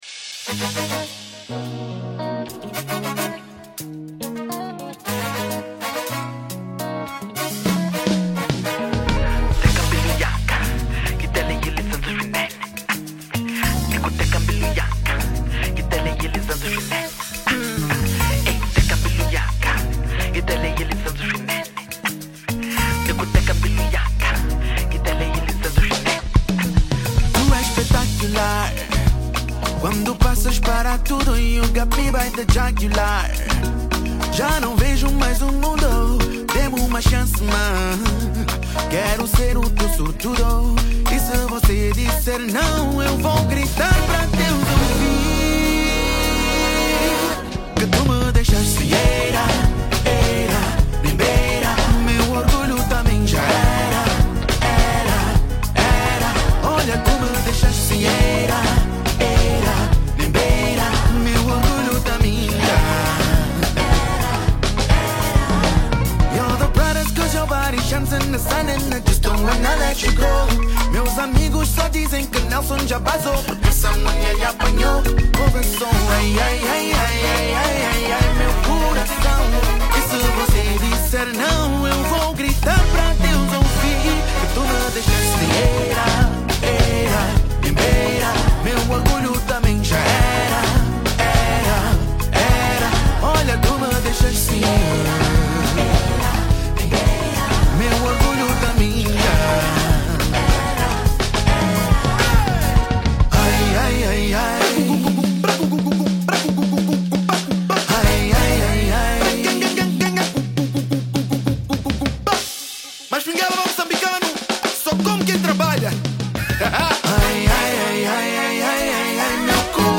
Genero: R&B/Soul